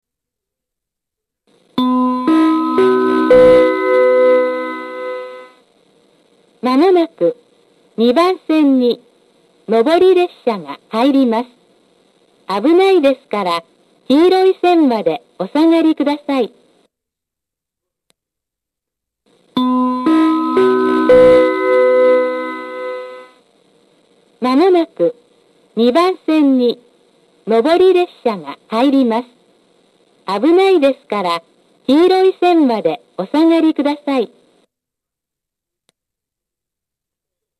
◆旧放送◆
カンノ製作所製の放送で、音量が大きく音割れ気味でした。
上り接近放送
下り接近放送